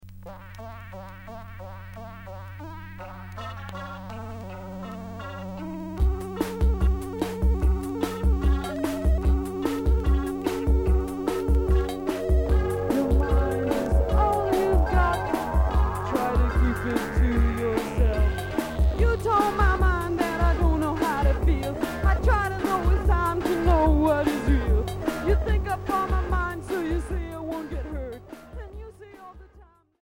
Cold wave Punk